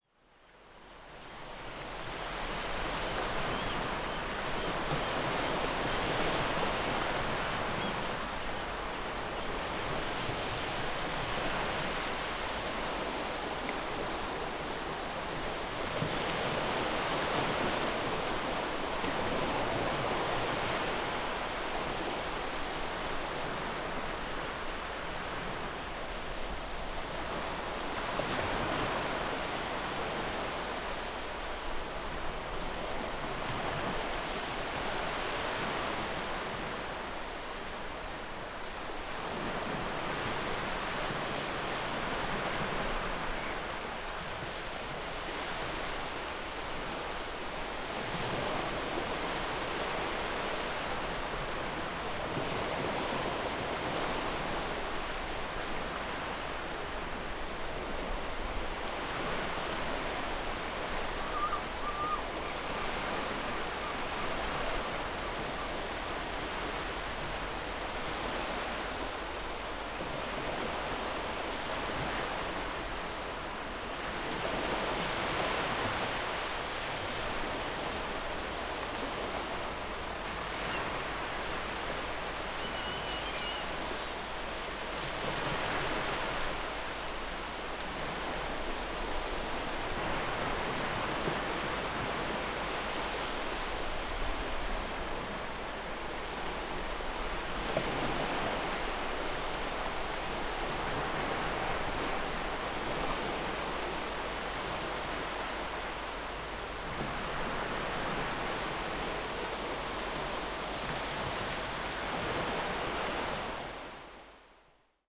دانلود صدای طبیعت و پرندگان